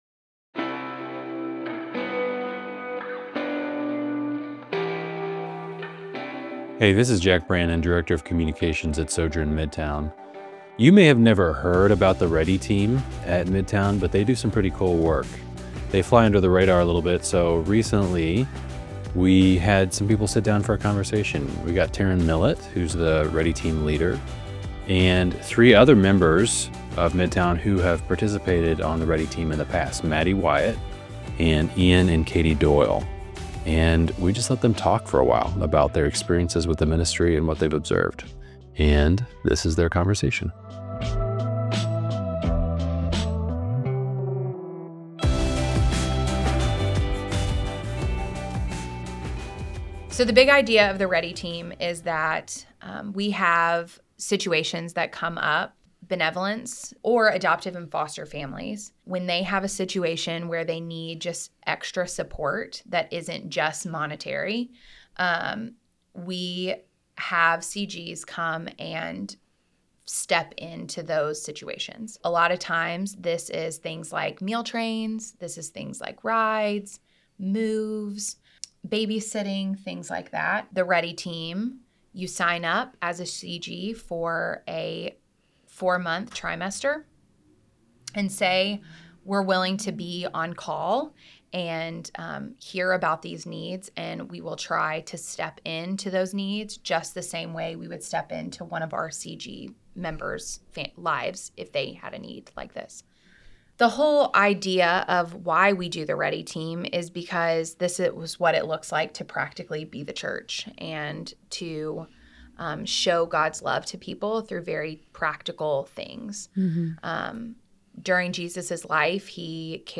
Ready Team: Servant Interview
Ready+Team+Roundtable+-+Audio+Only.mp3